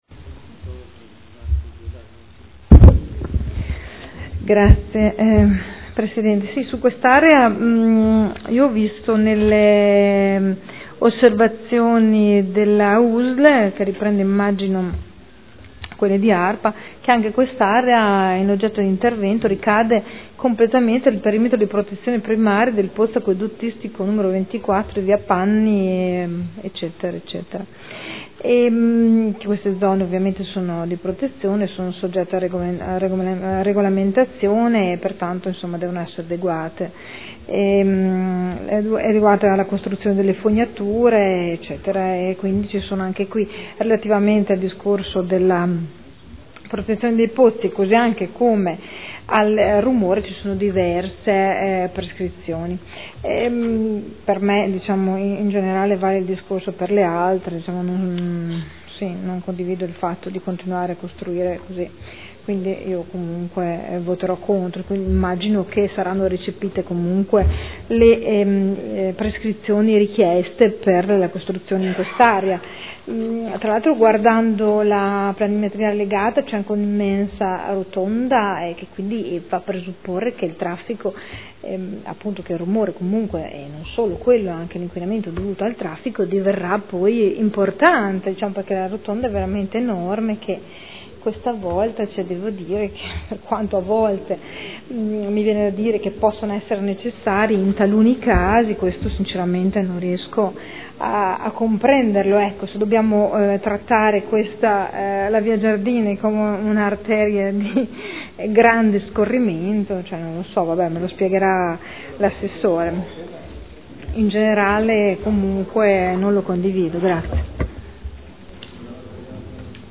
Seduta del 15/07/2013. Dibattito. Zona elementare 280 Area 01 e Area 06 – Via Giardini – Variante al POC – RUE con valore ed effetti di Piano Urbanistico Attuativo (PUA)